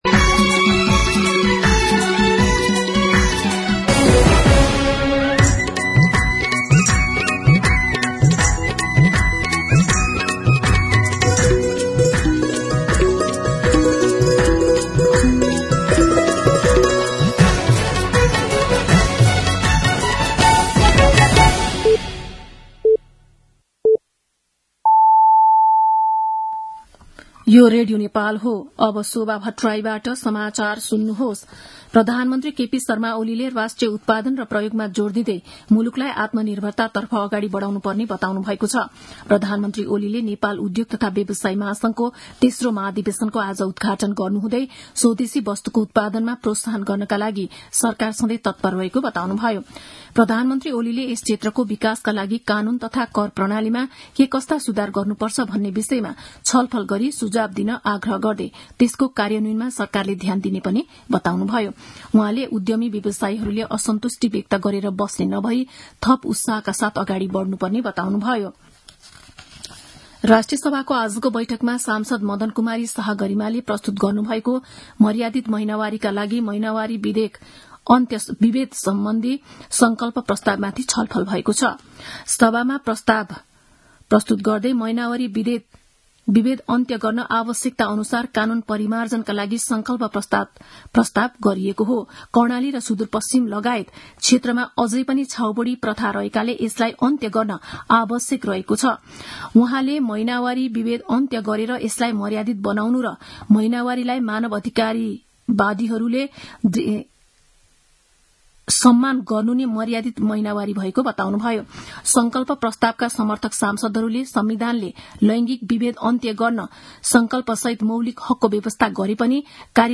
दिउँसो ४ बजेको नेपाली समाचार : ८ चैत , २०८१
4pm-news-.mp3